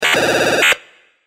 دانلود آهنگ هشدار 6 از افکت صوتی اشیاء
دانلود صدای هشدار 6 از ساعد نیوز با لینک مستقیم و کیفیت بالا
جلوه های صوتی